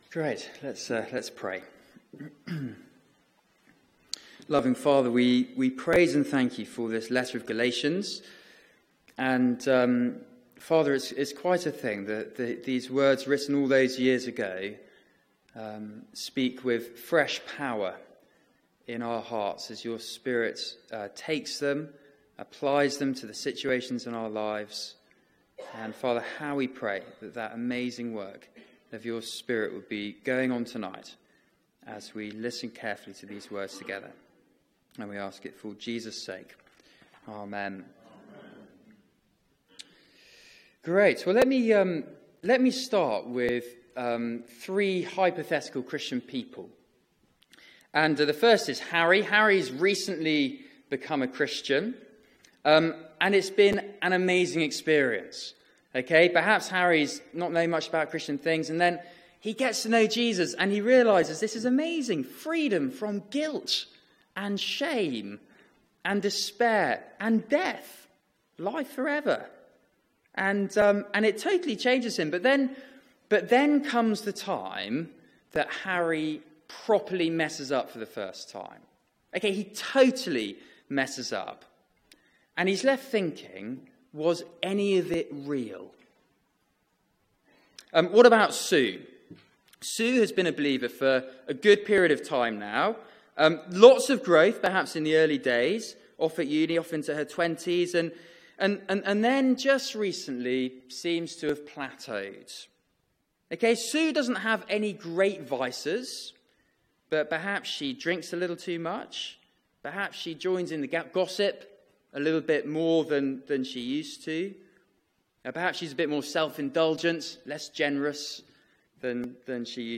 Media for 6:30pm Service on Sun 05th Nov 2017 18:30 Speaker
Theme: The Spirit of freedom Sermon